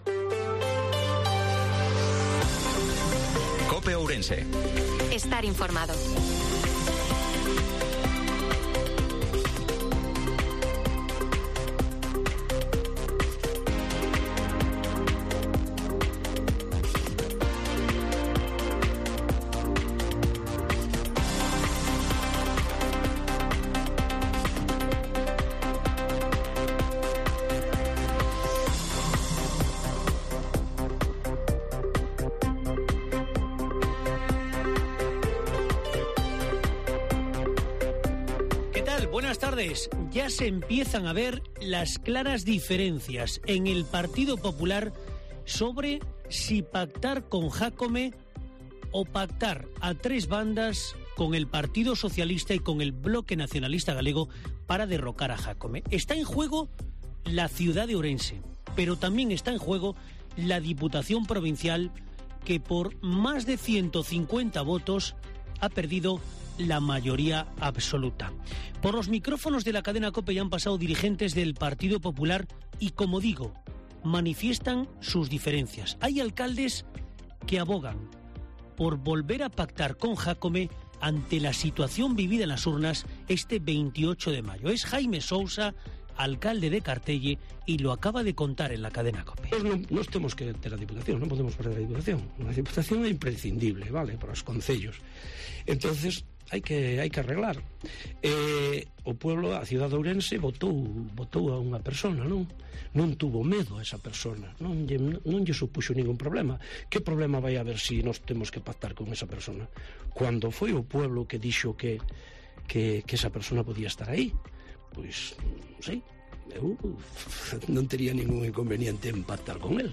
INFORMATIVO MEDIODIA COPE OURENSE-30/05/2023